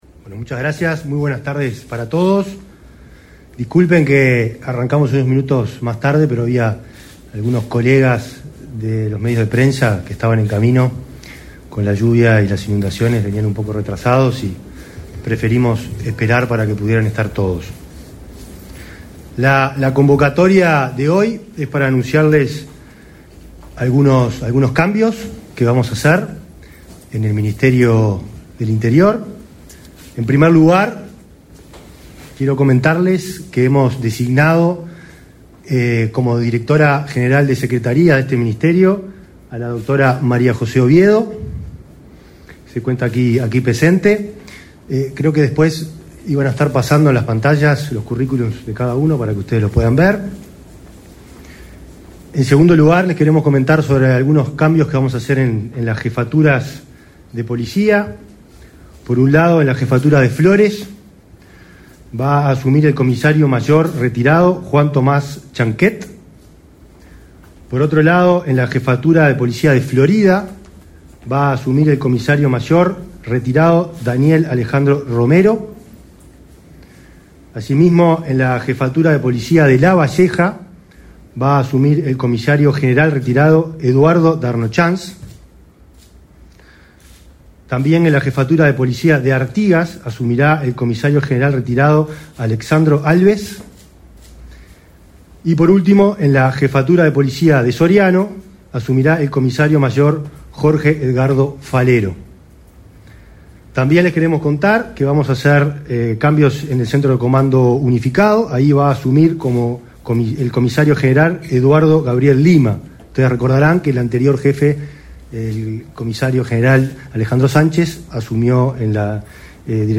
Conferencia de prensa del ministro del Interior, Nicolás Martinelli
El ministro Martinelli efectuó una conferencia de prensa.